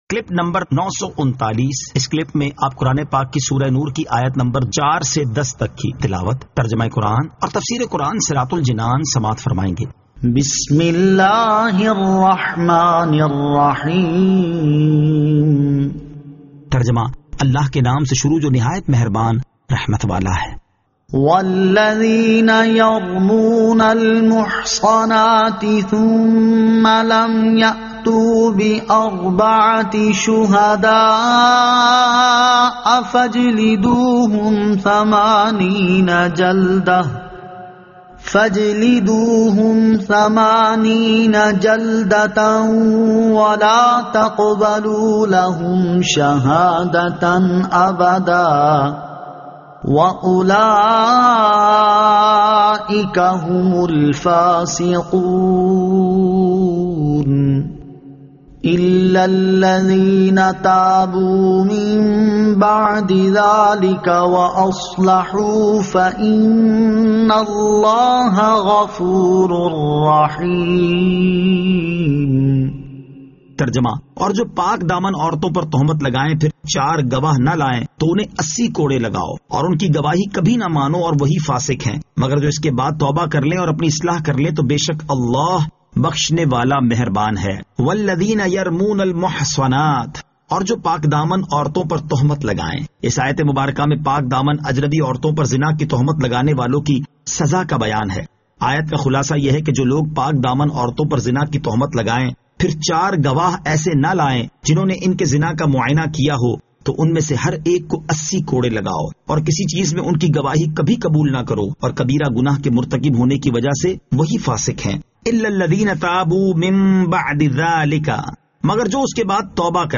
Surah An-Nur 04 To 10 Tilawat , Tarjama , Tafseer